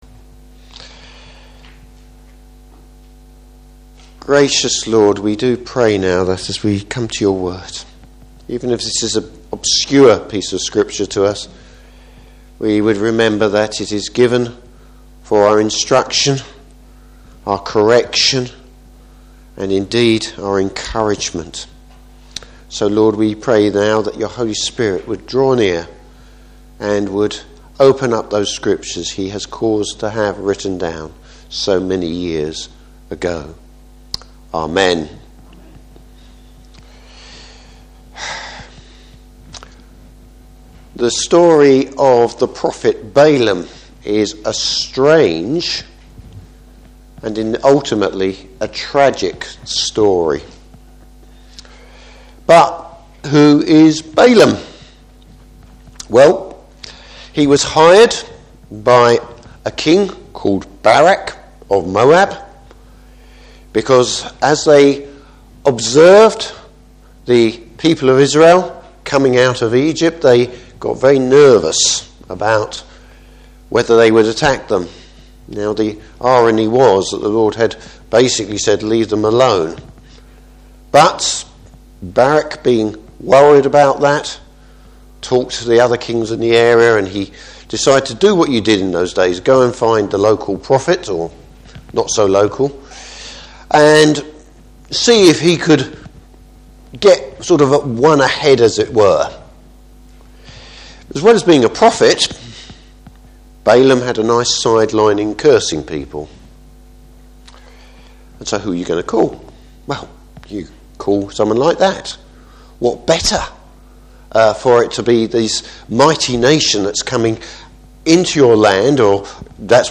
Service Type: Morning Service A Pagan Prophet predicts Christ’s coming.